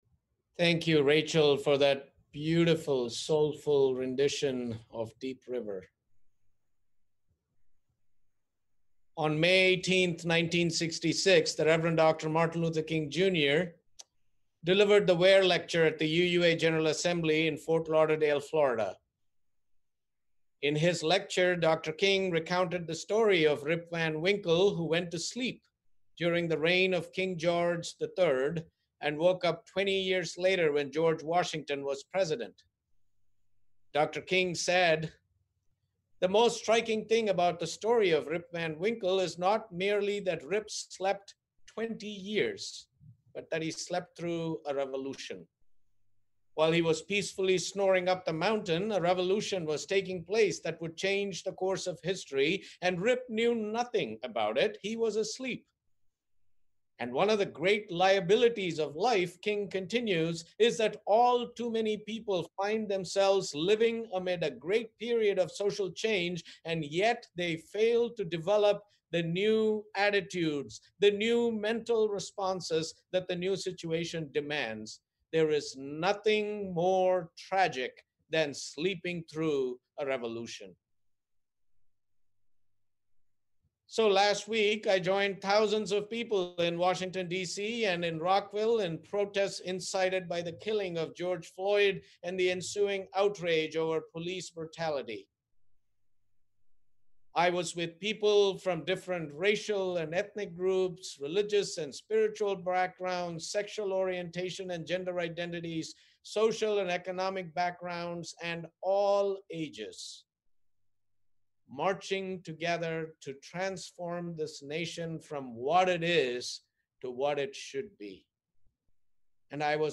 Sermon: Getting Into Position